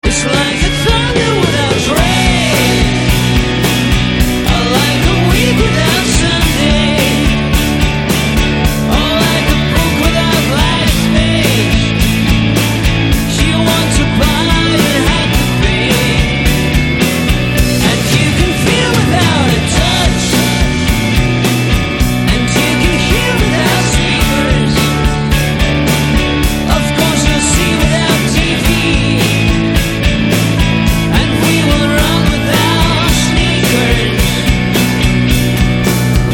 Назад в Rock